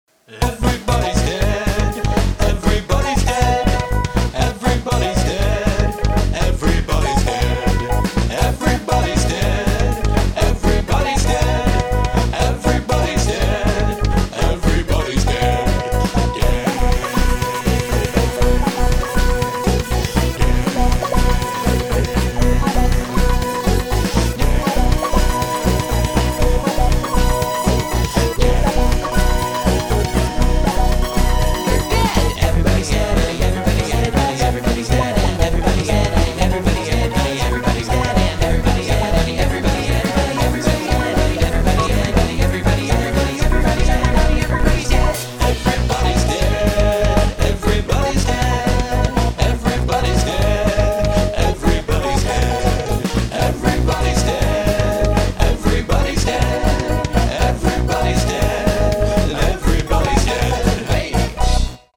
Vocals are pretty good here.